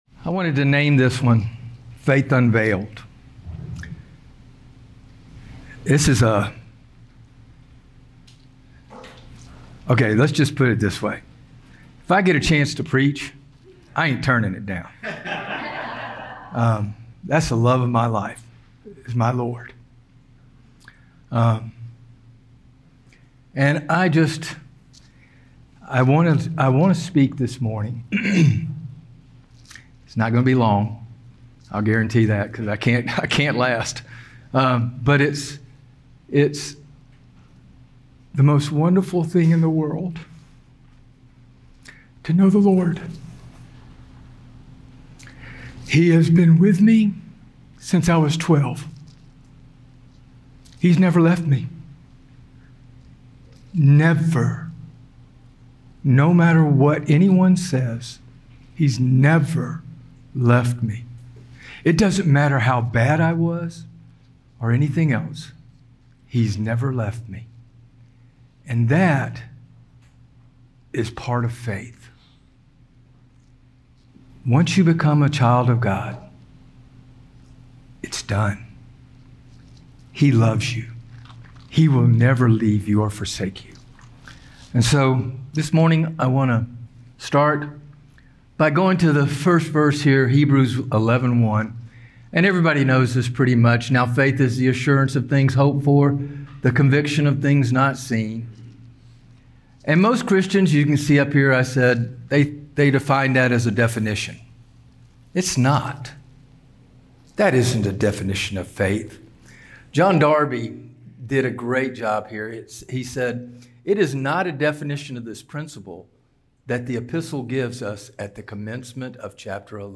Sermons | Good News Church Georgia